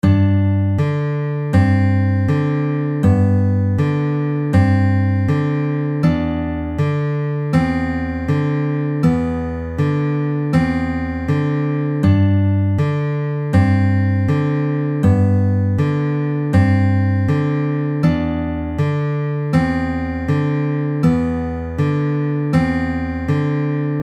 Exercise 6 - Pinching a simple melody over 2 chords
Then rather than having the 6th (thickest) string at the 3rd fret, we leave that string open.
It would now sound like a piece of music rather than a practice exercise.